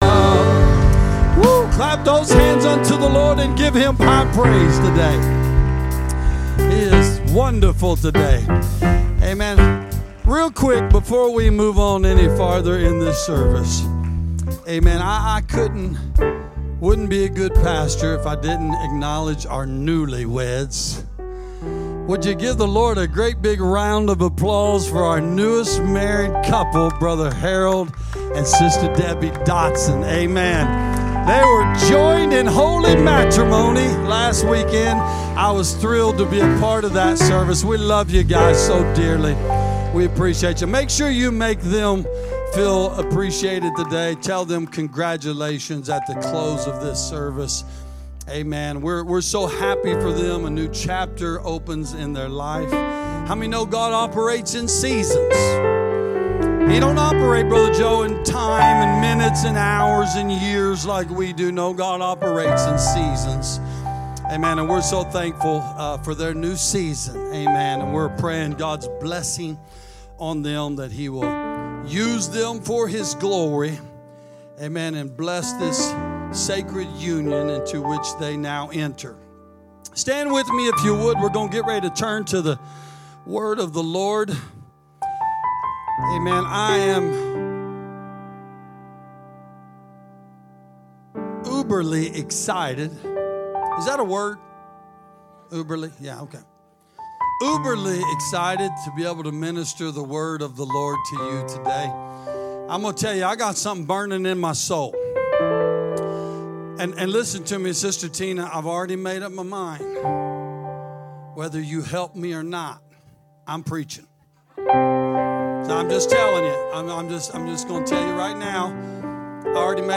:) This episode is the most recent message I gave at Calvary.